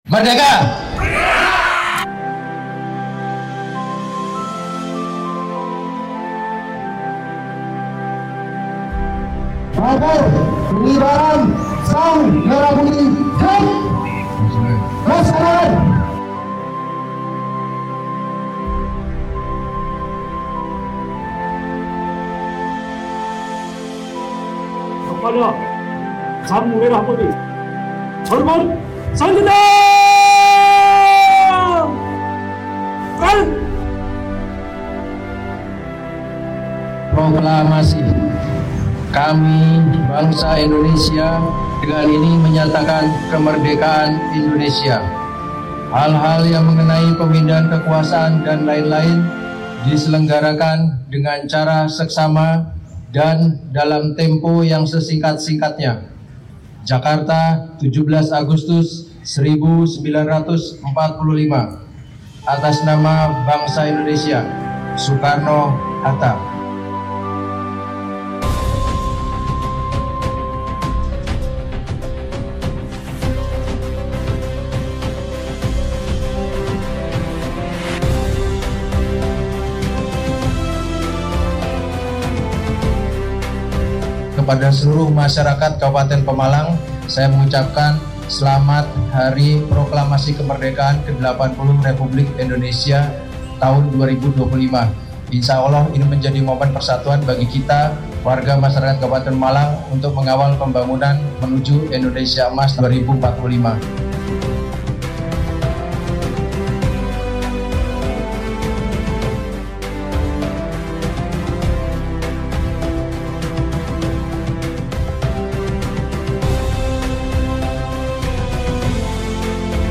Upacara berlangsung khidmat dan penuh suka cita, diikuti oleh Forkopimda, jajaran pemerintah daerah, para pelajar, organisasi masyarakat, serta masyarakat Pemalang dari berbagai penjuru. Kita jadikan semangat kemerdekaan sebagai penguat persatuan, keteguhan tekad, dan semangat bersama dalam membangun Pemalang yang lebih maju, berdaya saing, dan sejahtera.